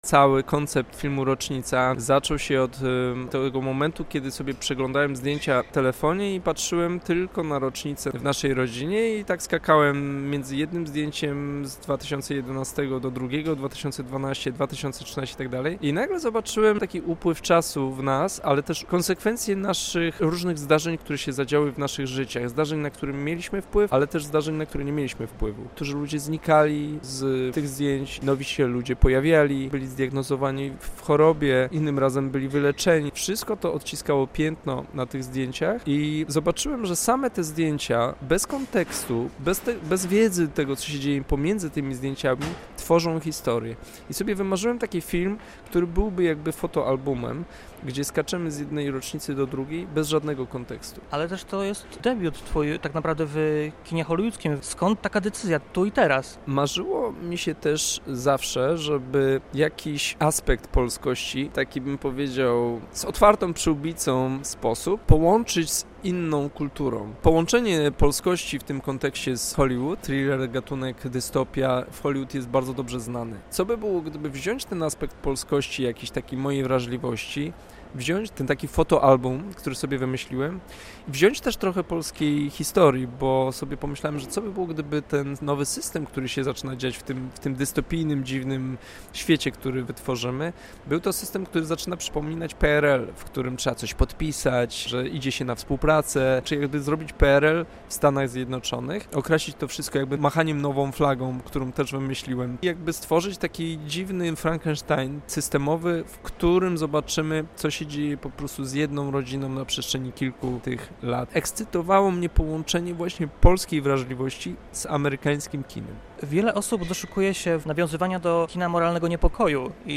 Z Janem Komasą rozmawia